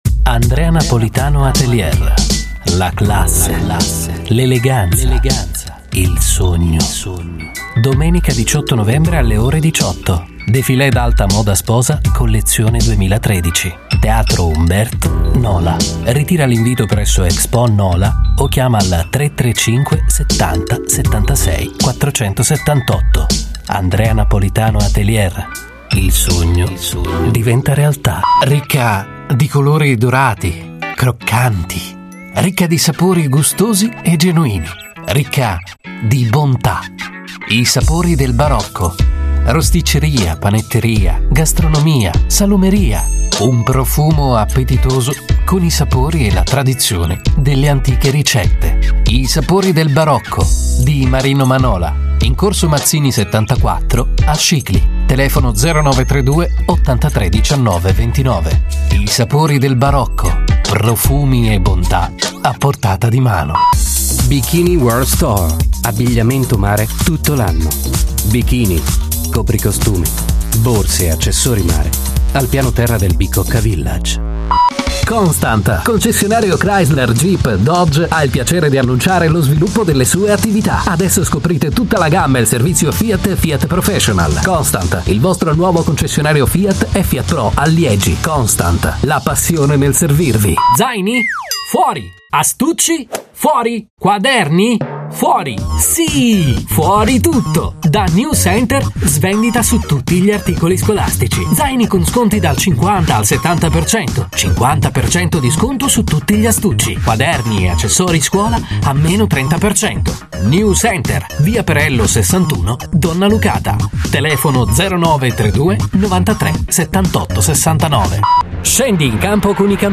A versatile and multipurpose voice, suitable for any production. Extremely flexible native italian voice.
Sprechprobe: Werbung (Muttersprache):